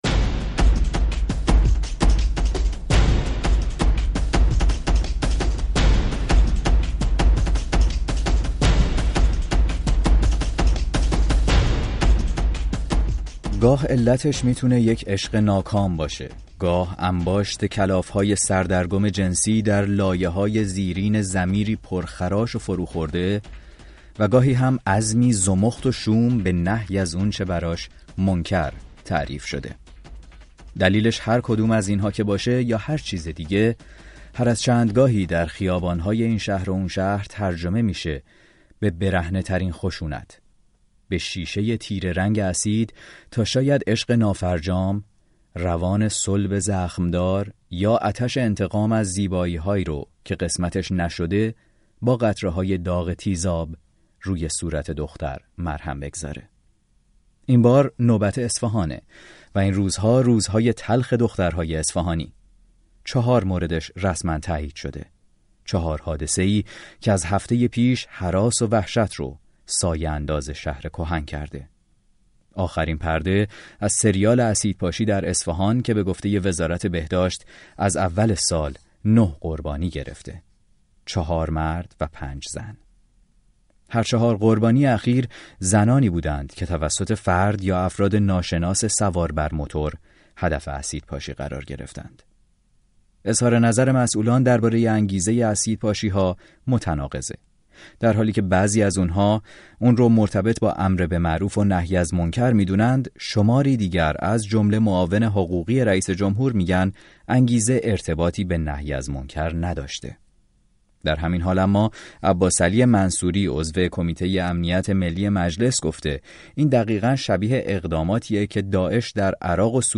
برنامه رادیویی «ساعت ششم» درباره اسیدپاشی در اصفهان